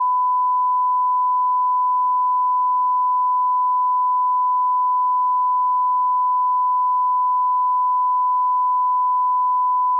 1000HZ.WAV